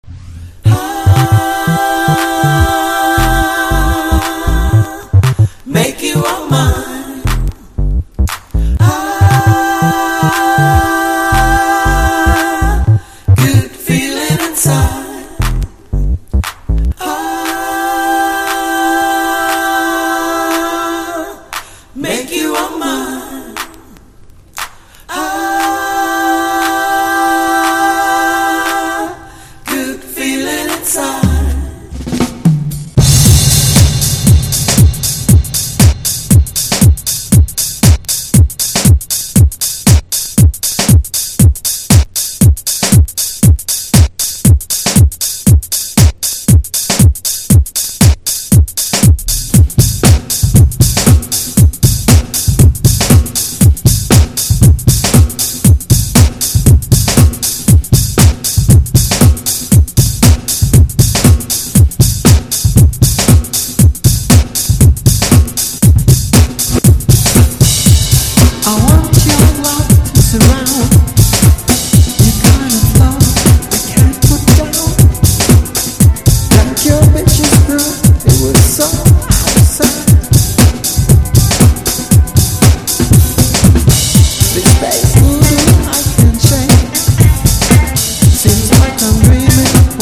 TECHNO / DETROIT / CHICAGO# BREAK BEATS / BIG BEAT